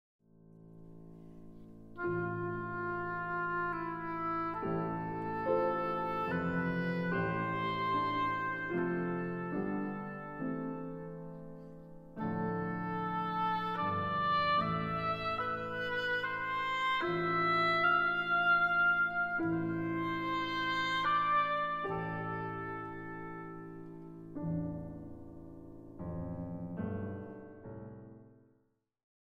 richly expressive woodwind palette